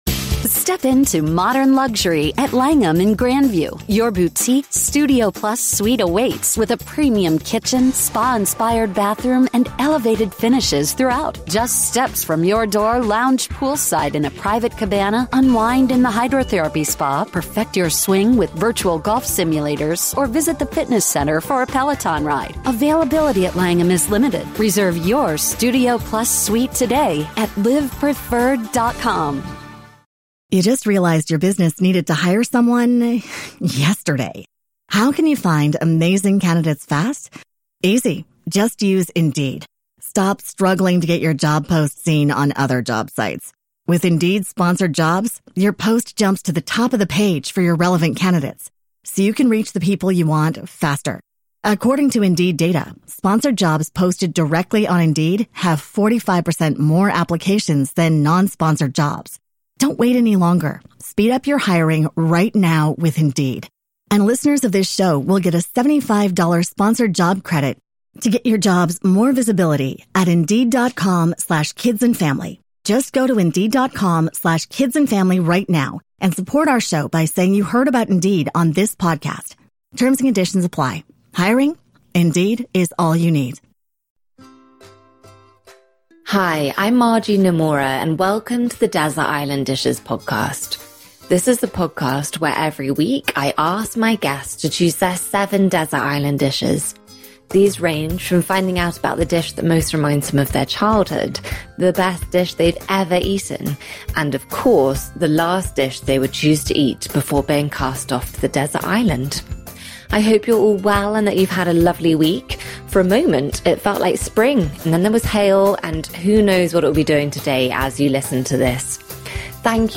My guest today is Stanley Tucci.